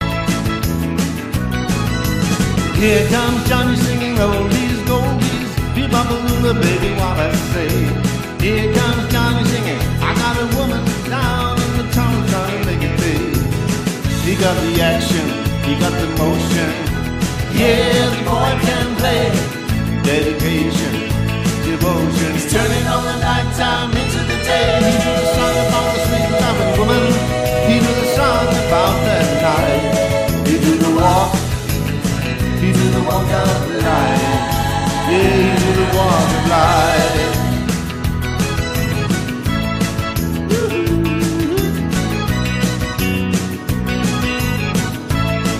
Hit på hit på hit - duo eller trio